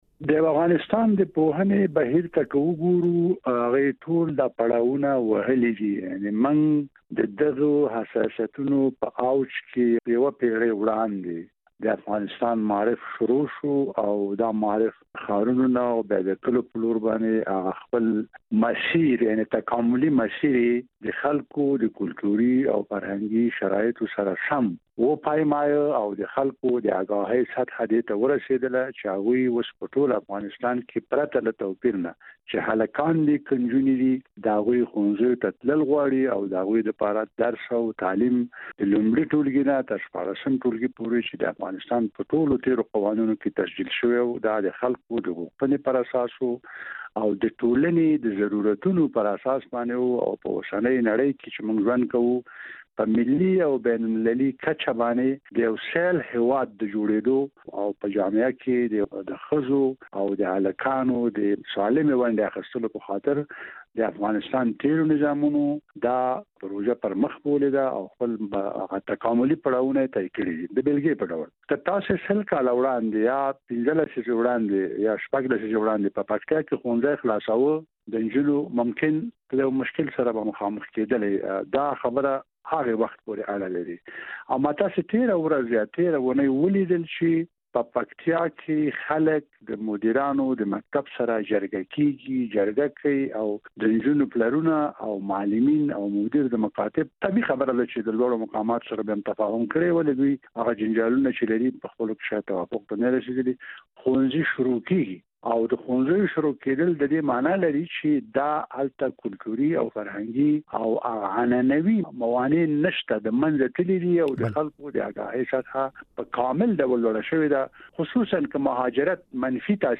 د صديق پتمن مرکه